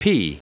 Prononciation du mot pea en anglais (fichier audio)
Prononciation du mot : pea